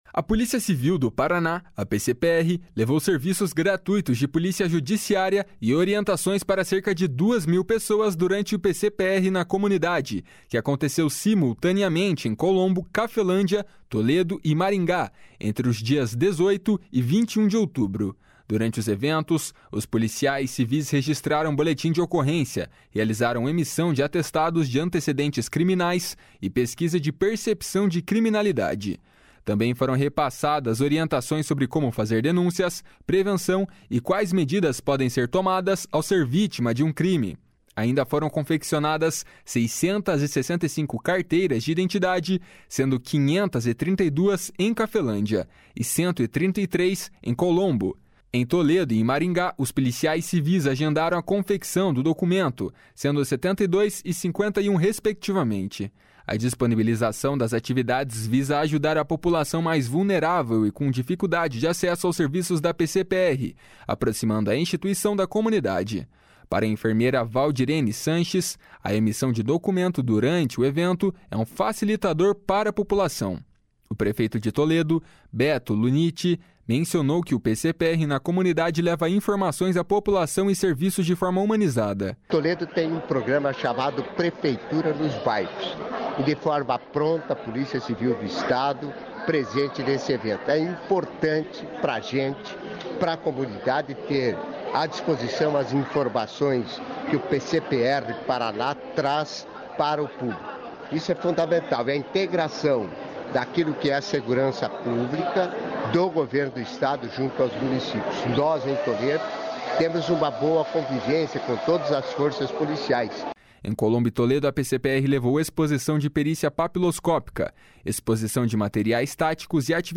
O prefeito de Toledo, Beto Lunitti, mencionou que o PCPR na Comunidade leva informação à população e serviços de forma humanizada. // SONORA BETO LUNITTI //